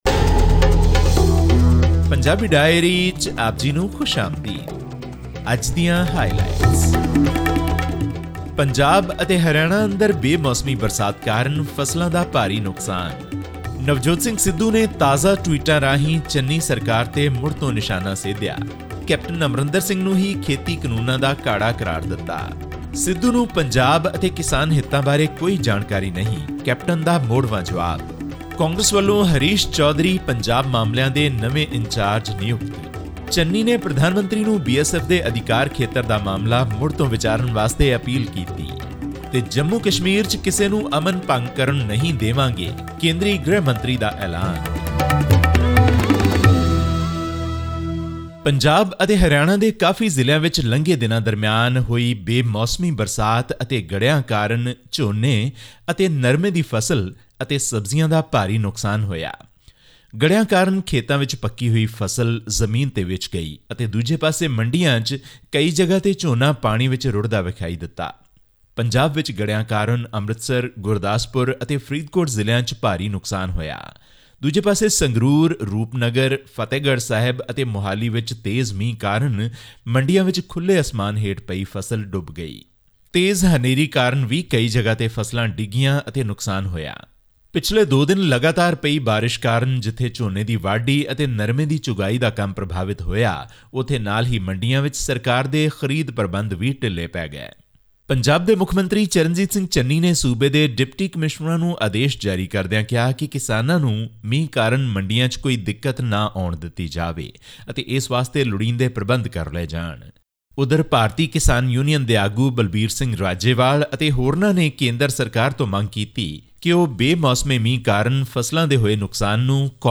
In the past couple of days, heavy rainfall has inundated thousands of acres of agricultural land in Punjab, damaging standing crops and bringing misery for the farmers. This and more in our weekly news update from the north Indian state.